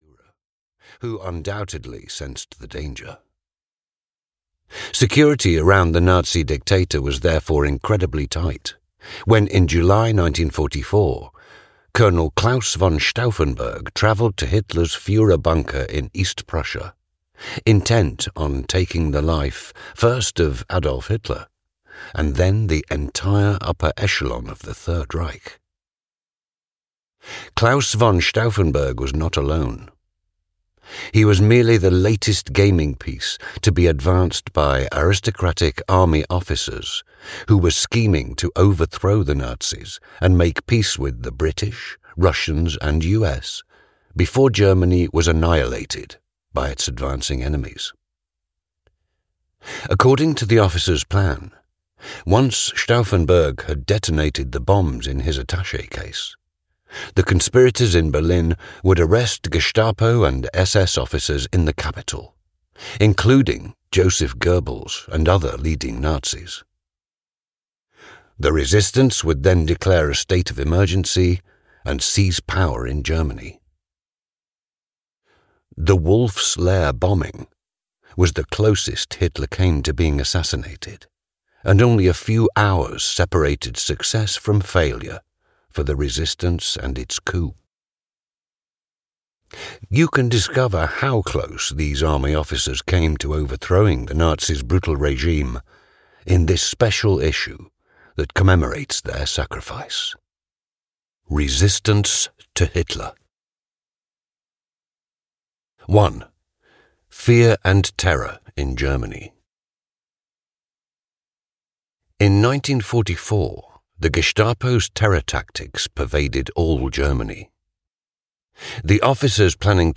Kill Hitler (EN) audiokniha
Ukázka z knihy